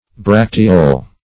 Search Result for " bracteole" : Wordnet 3.0 NOUN (1) 1. a small bract ; [syn: bracteole , bractlet ] The Collaborative International Dictionary of English v.0.48: Bracteole \Brac"te*ole\, n. [L. bracteola, dim. of bractea.